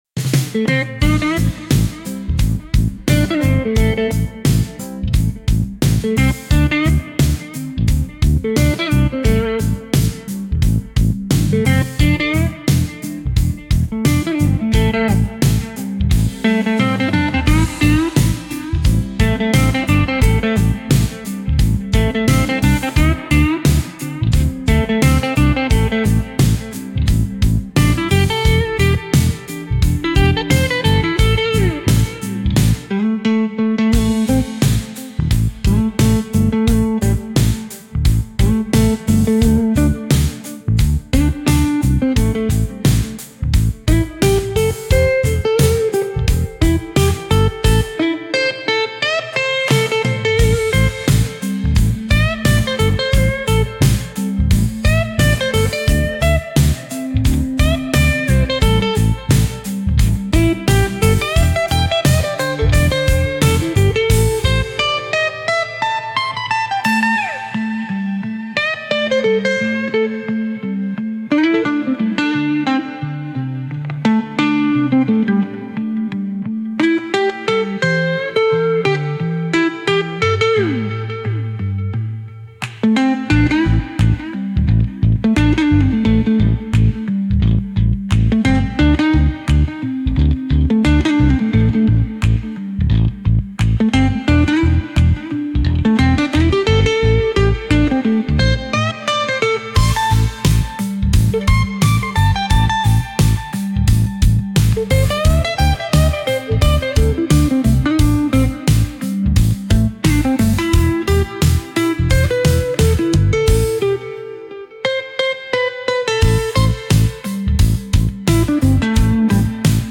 Instrumental - RLMradio Dot XYZ - 4 mins (2)